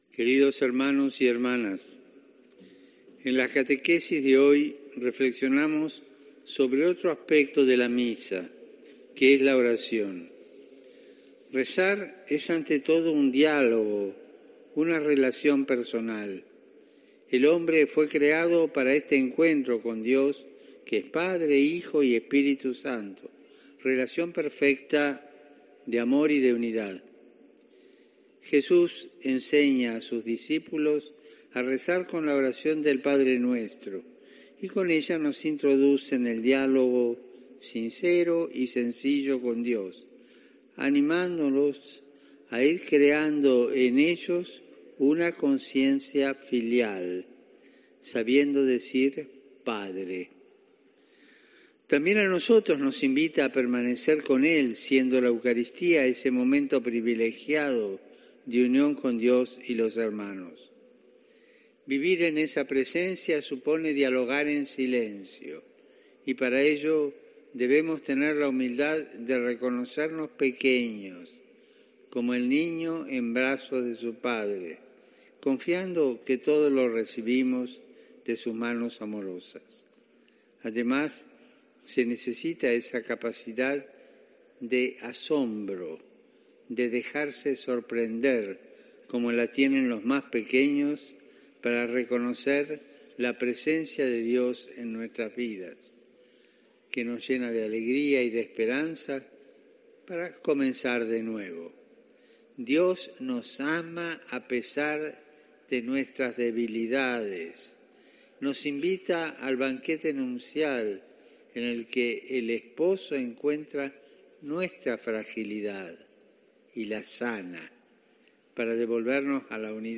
El Papa Francisco ha querido remarcar durante la catequesis de los miércoles en la Plaza de San Pedro que rezar es hablar con Dios y no repetir como "papagayos".